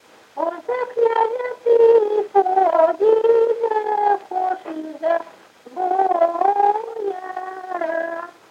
Произнесение слова хочешь как хошь
/хо”ш за-кн’а”-з’а тыы” поо-д’и” да-хо”ш за-боо-йа-ра:”/